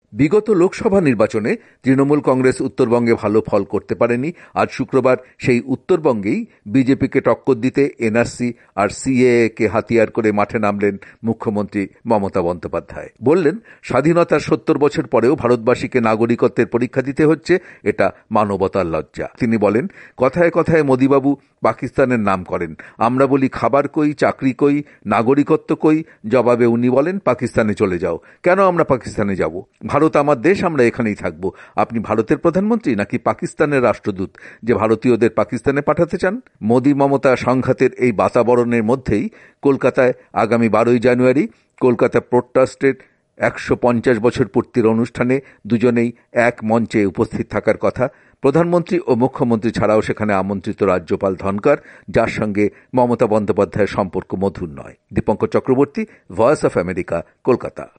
কলকাতা থেকে
রিপোর্ট।